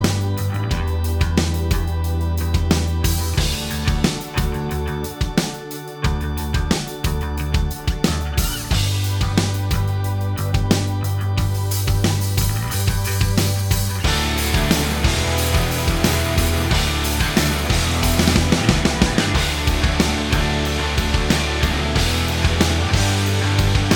Minus Lead Guitars Pop (2000s) 4:53 Buy £1.50